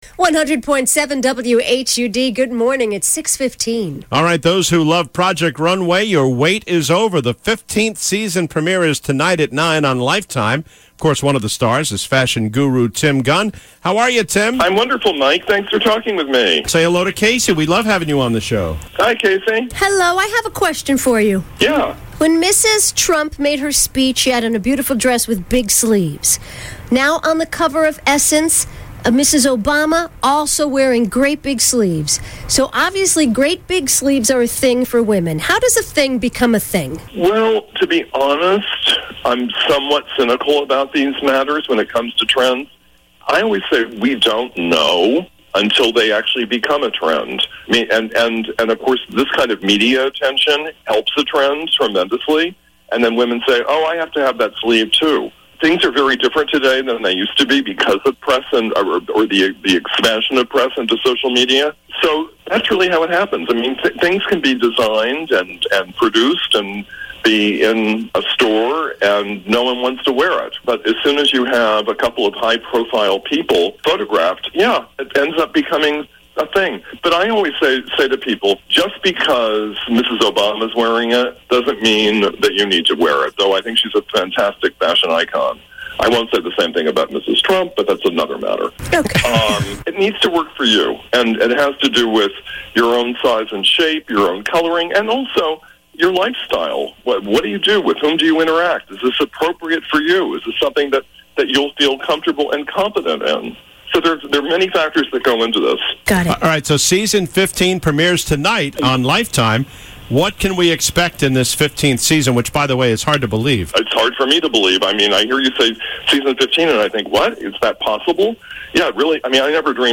Interview with Tim Gunn-Project Runway